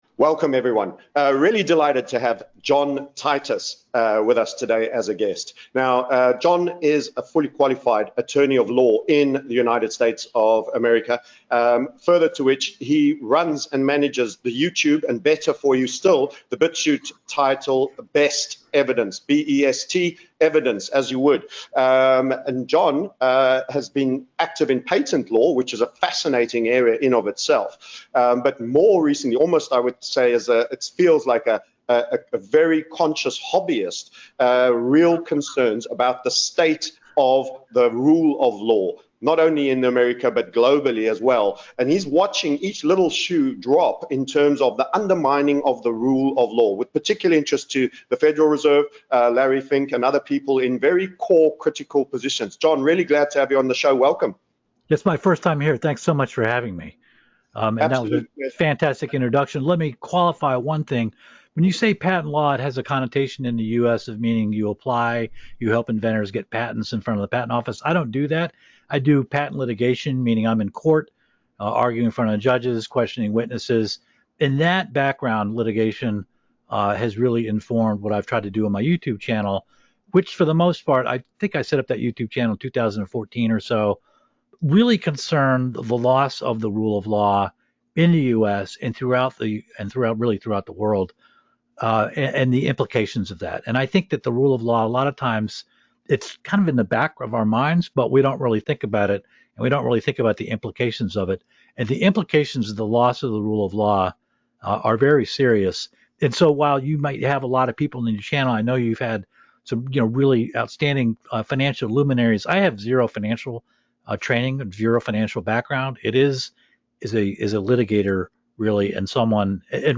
This interview was originally released on April 25, 2022